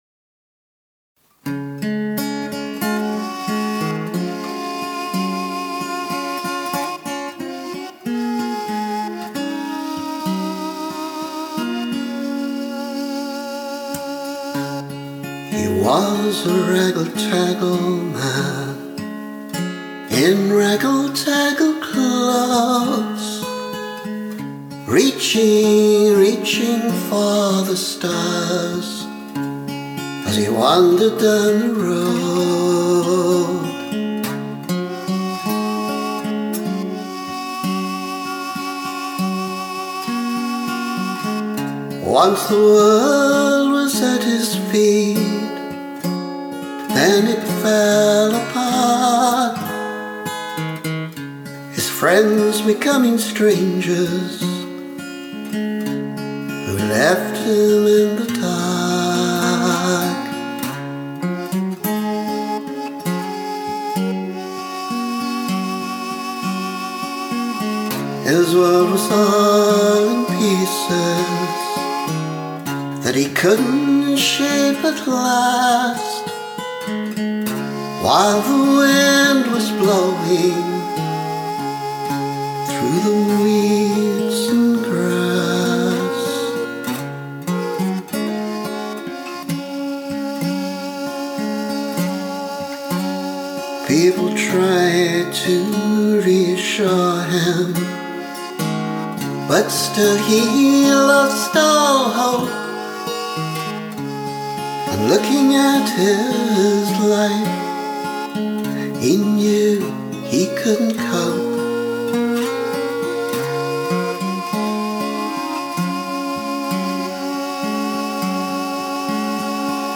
with a traditional tune drastically arranged by me.
The words have been published here before, but this demo is better recorded than the one with the previous version.
The tune is a variation on a tune that Jean Ritchie used to sing as ‘False Sir John’.